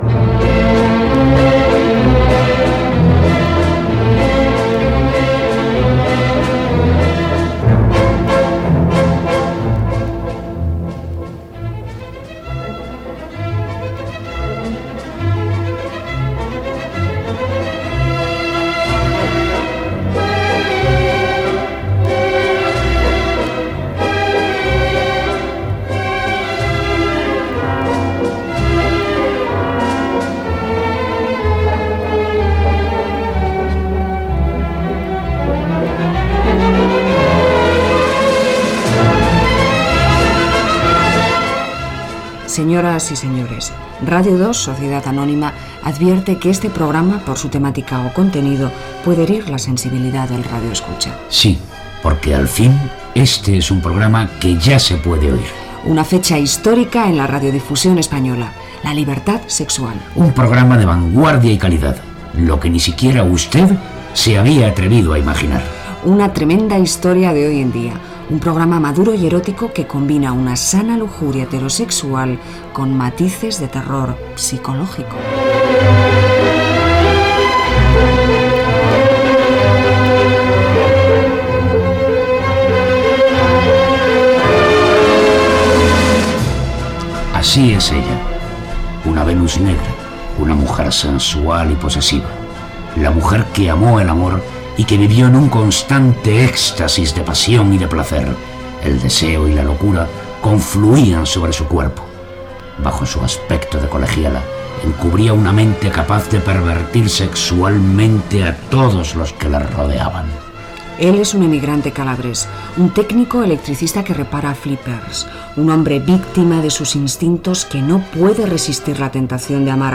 Sintonia del programa, advertència a l'audiència, presentació sobre el suposat contingut sexual del programa, una venus negra, un electricista i una prostituta
Musical